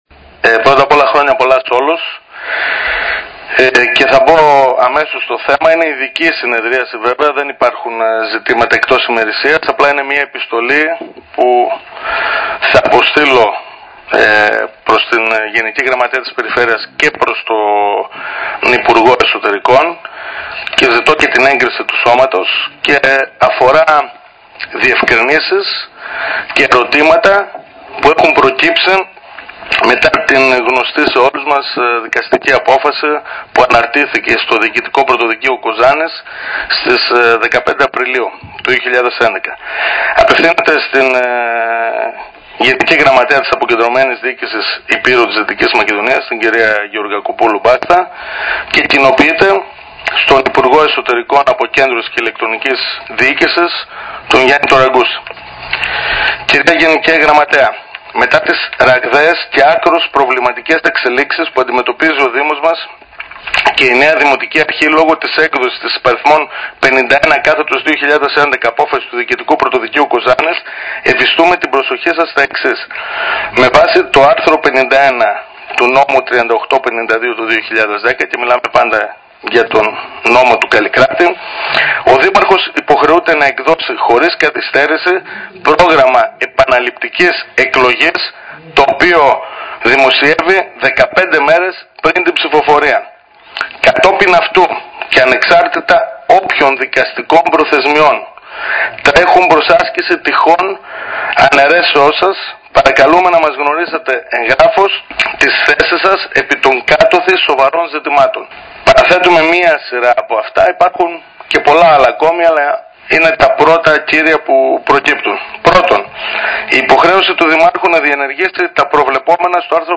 Επιστολή με την οποία ζητά να μάθει τον τρόπο διεξαγωγής των Δημοτικών εκλογών στον Δήμο Γρεβενών στέλνει ο Δημοσθένης Κουπτσίδης στο Υπουργείο Εσωτερικών. Θέτει μία σειρά ερωτημάτων για το πως θα οδηγηθούμε σε εκλογές και ζητά συγκεκριμένες απαντήσεις. Την επιστολή διάβασε στην έκτακτη συνεδρίαση του Δημοτικού Συμβουλίου που πραγματοποιήθηκε την Τετάρτη 27 Απριλίου με μοναδικό θέμα τη συζήτηση για τον Ισολογισμό του Δήμου.